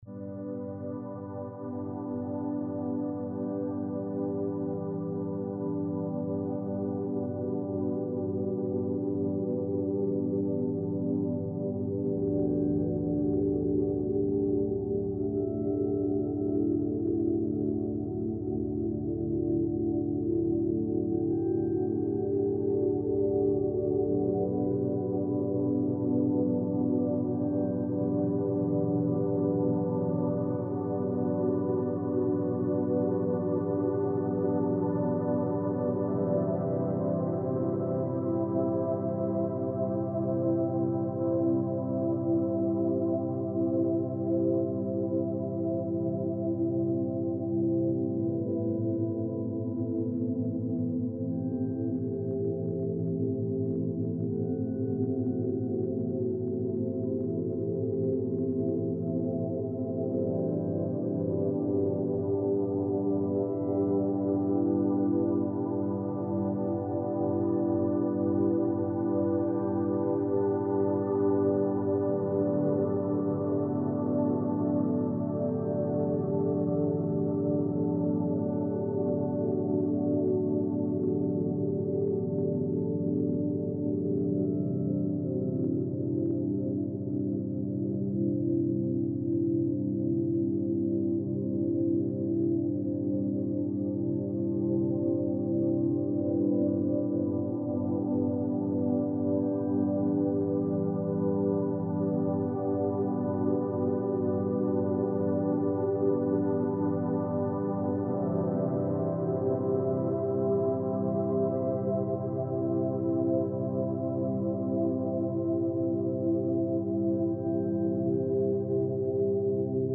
New Moon Energy - 30hz - Beta Binaural Beats - Mind Brightening Frequency
Calm Chic Sound Rituals — a softer way to focus.